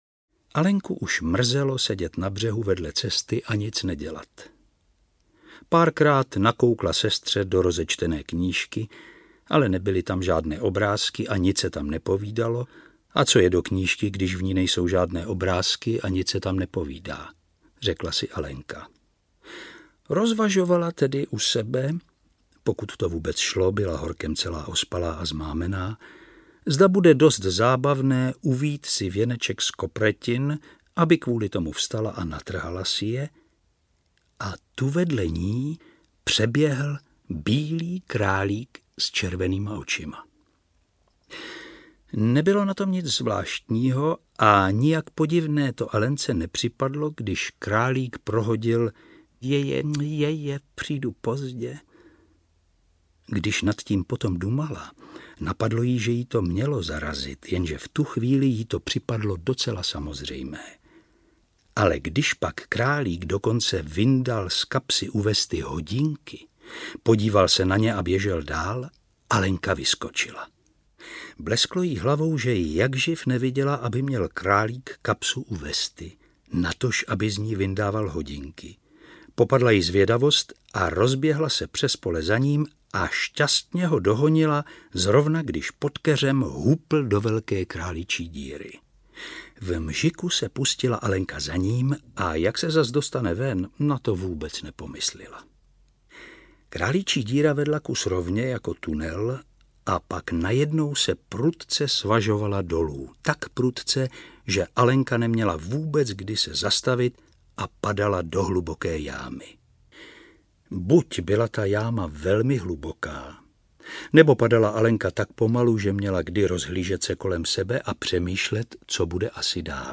Alenka v říši divů audiokniha
Ukázka z knihy
• InterpretJiří Ornest